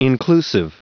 Prononciation du mot inclusive en anglais (fichier audio)
Prononciation du mot : inclusive